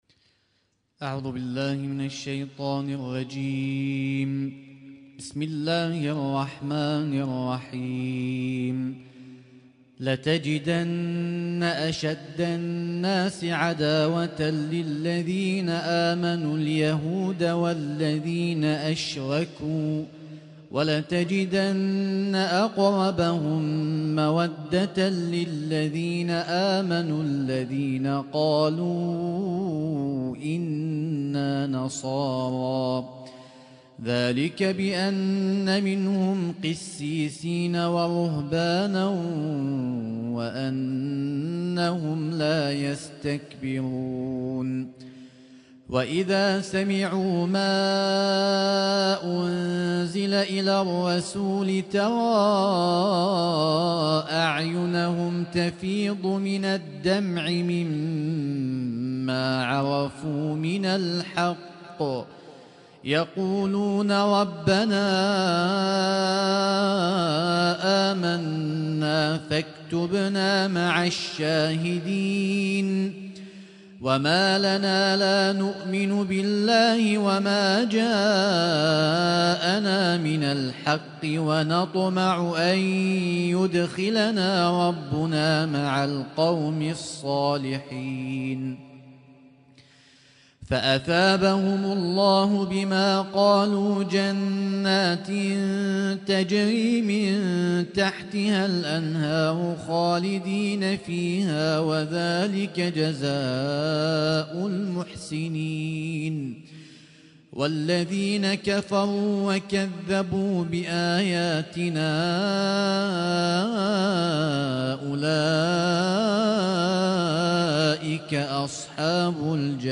اسم التصنيف: المـكتبة الصــوتيه >> القرآن الكريم >> القرآن الكريم - شهر رمضان 1446